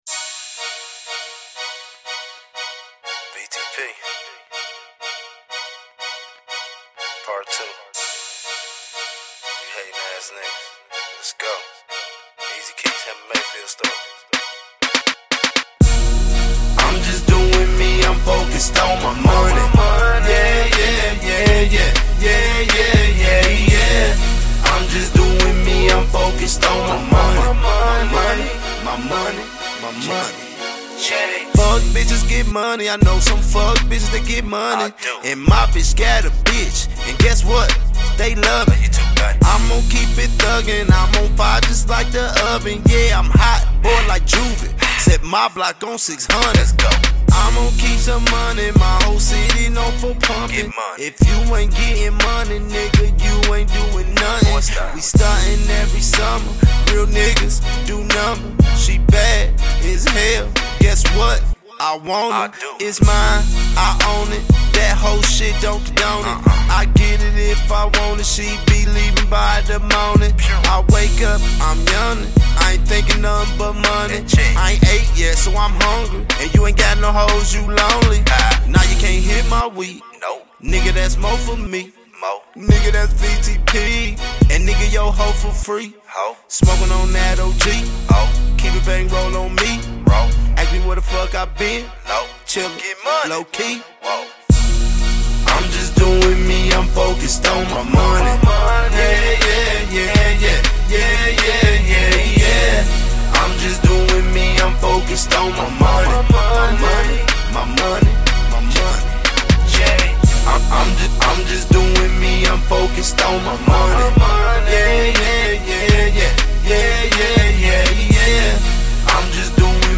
Indie
Description : Raw Gritty Midwest Music.